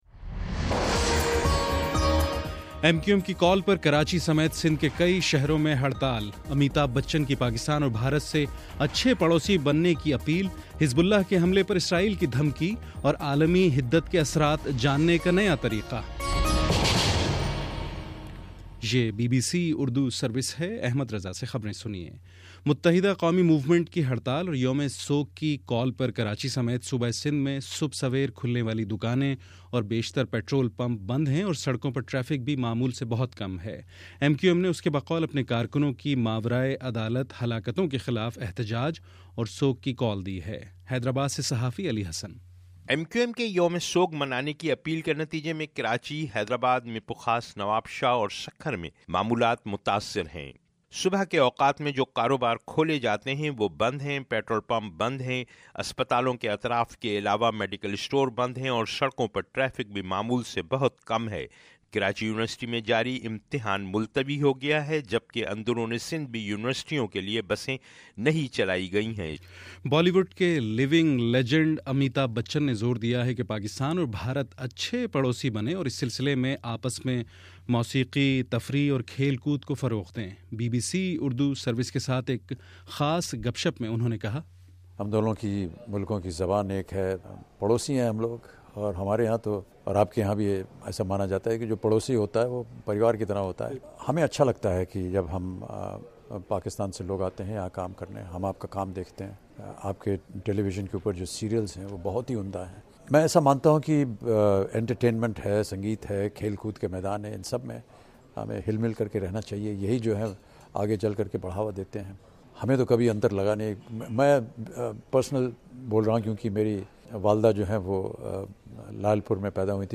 جنوری 29: صبح نو بجے کا نیوز بُلیٹن